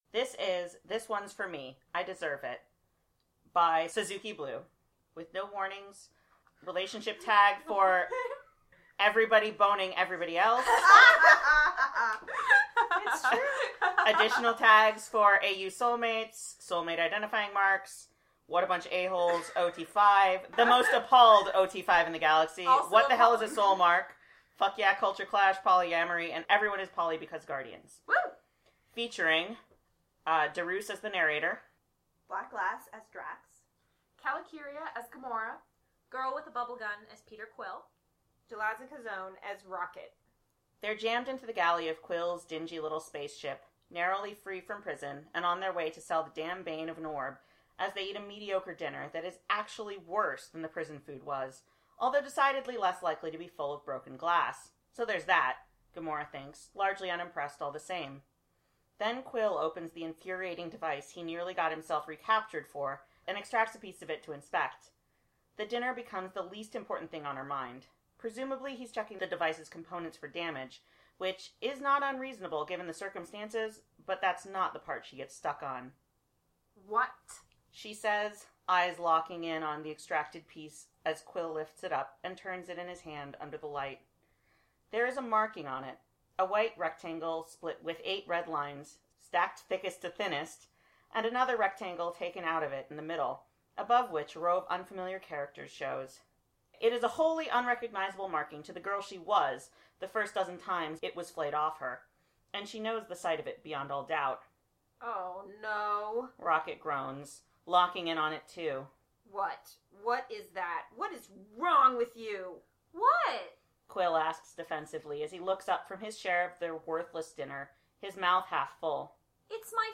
MP3 for download with outtakes [recommended] 16:15
This one's for me, I deserve it with outtakes.mp3